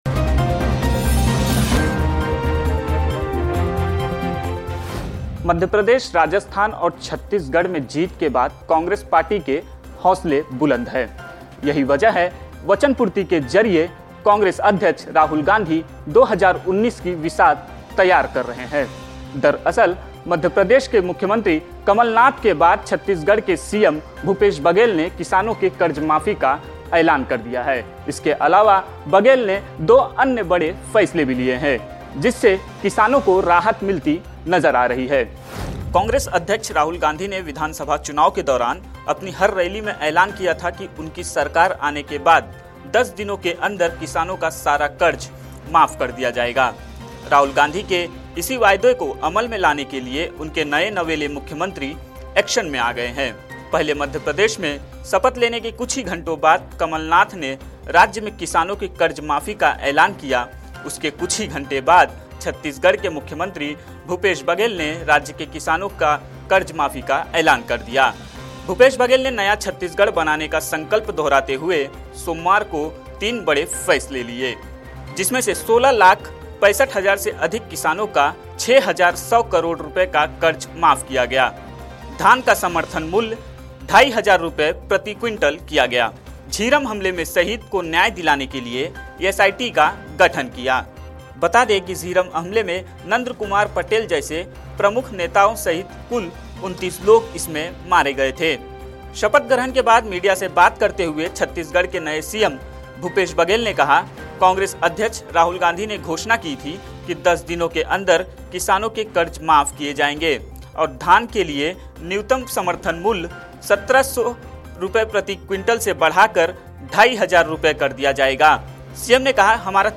न्यूज़ रिपोर्ट - News Report Hindi / कांग्रेस ने की किसानों की कर्जमाफी, नरेंद्र मोदी का बढ़ा टेंशन